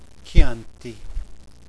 Italian Wine Pronunciation Guide
Click on a speaker symbol to hear the word spoken aloud.